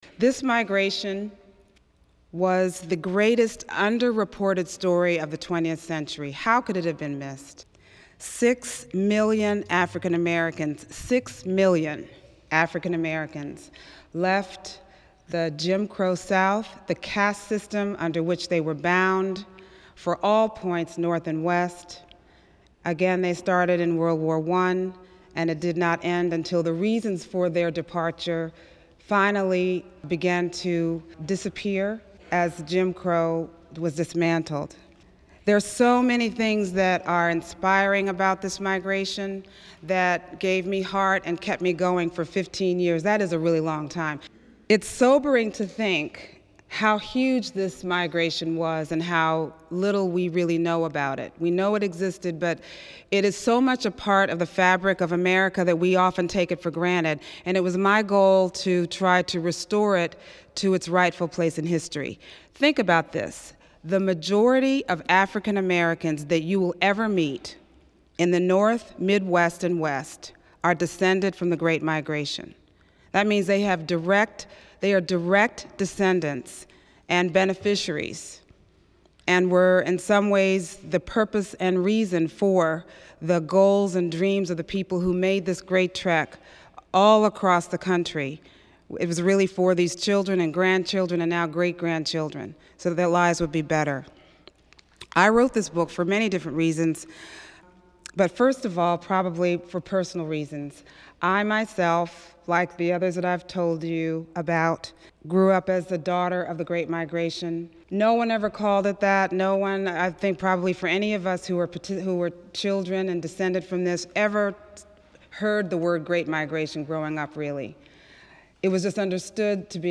Isabel Wilkerson in Oakland